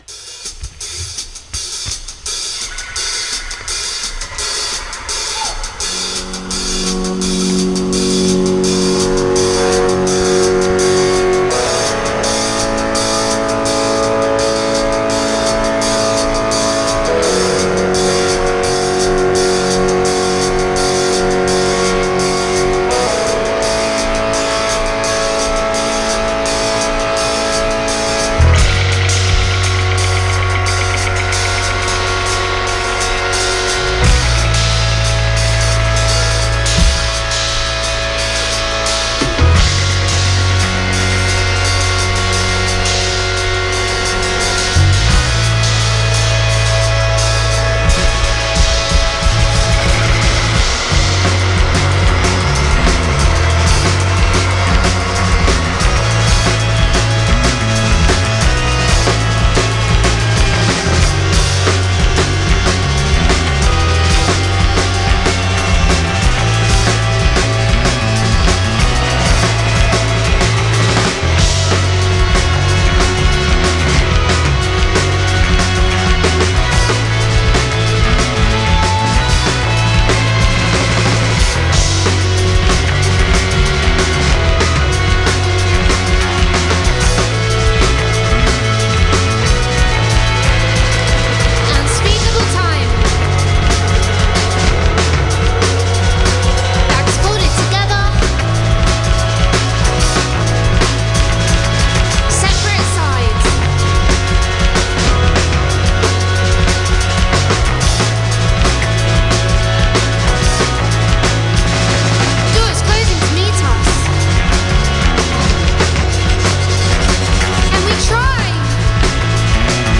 from the Float Away Festival in Sheffield
Since the release of their ambient-pop debut EP
glitching drums, flute, and cavernous shoegaze guitar